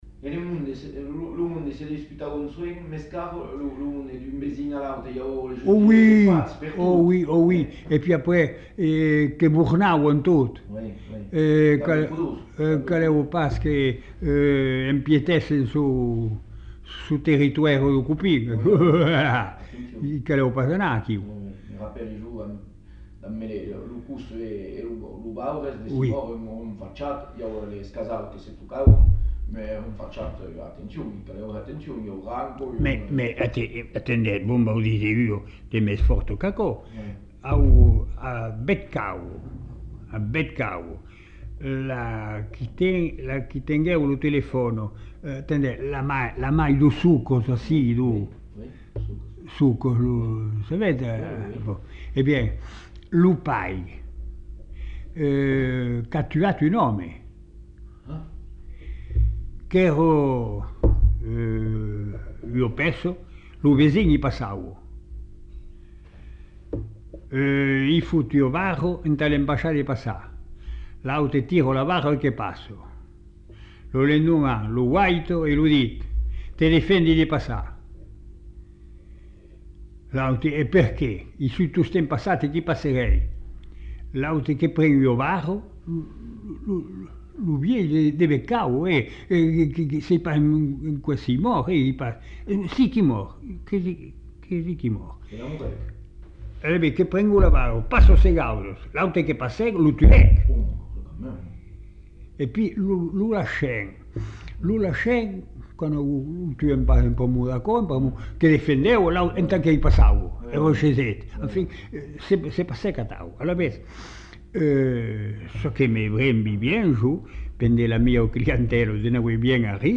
Lieu : Masseube
Genre : témoignage thématique